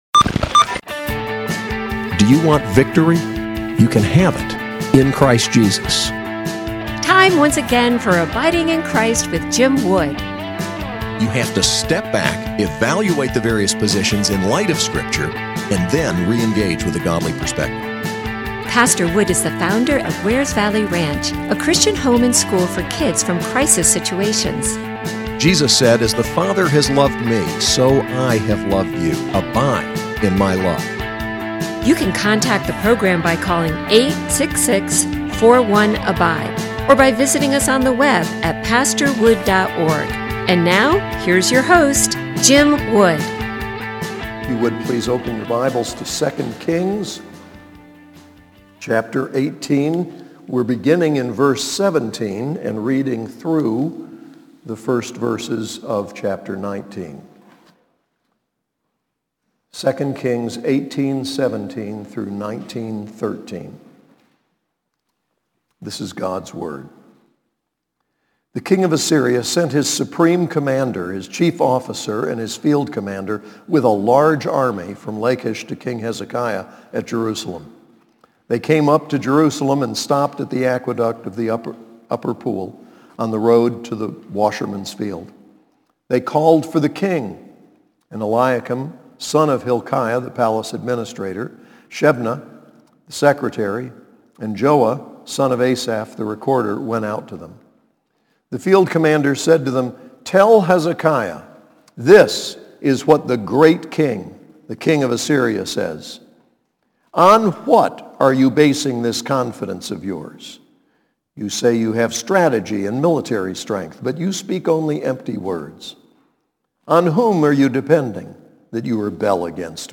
SAS Chapel: 2 Kings 18:17-19:13